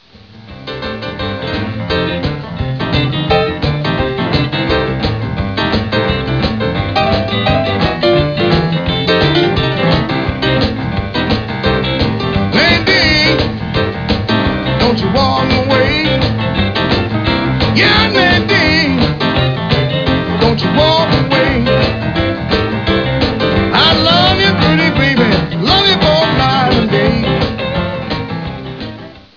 vocal range is low and earthy, but soulful all the same.